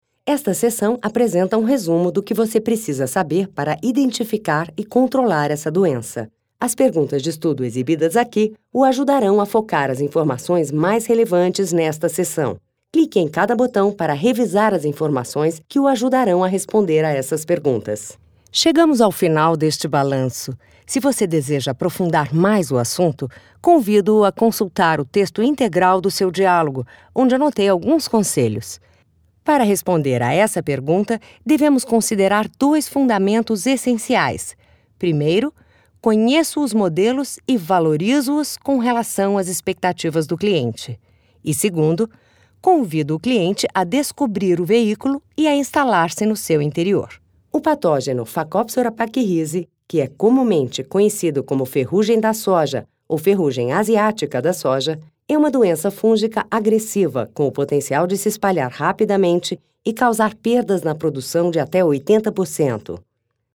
Brazilian native VO talent and actress with +20 years experience, records in BR Portuguese and English w/accents.
Sprechprobe: eLearning (Muttersprache):